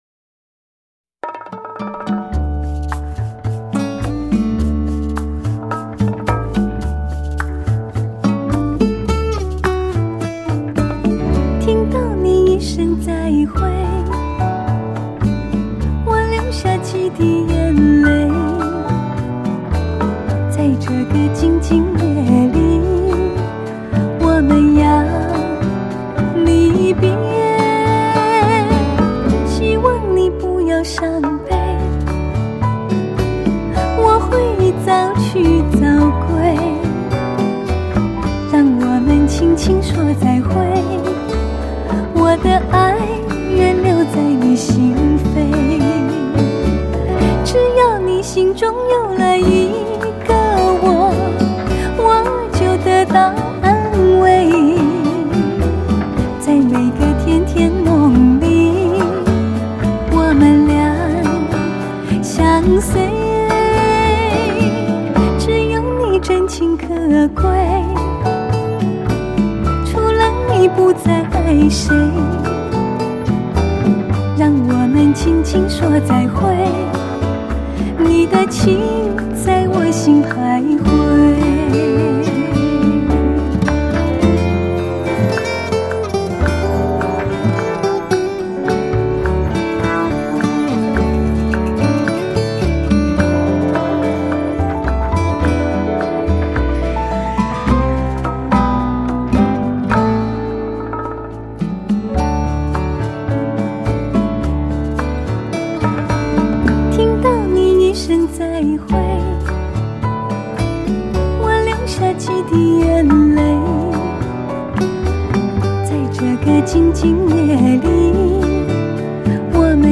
是以60-70年代最脍炙人口的的情歌为主，再增选几首不同时期的经典绝版，